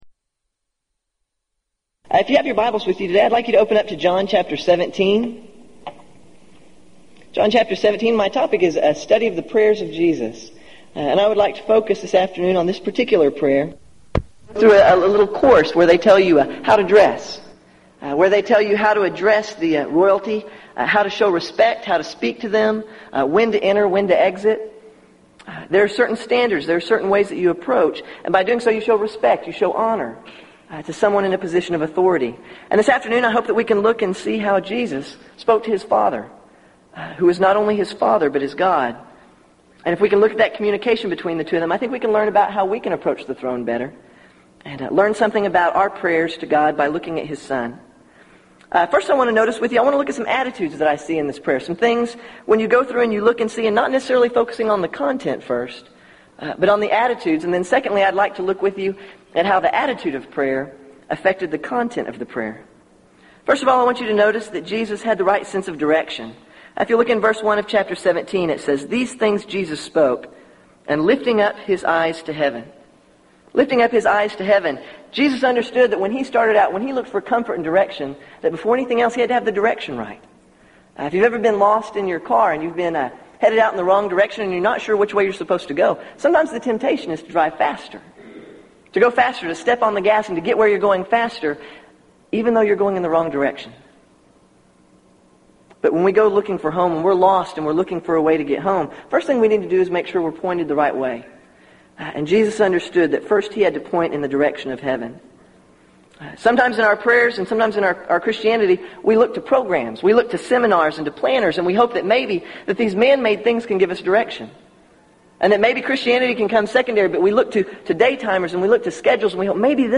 Event: 1998 Gulf Coast Lectures
lecture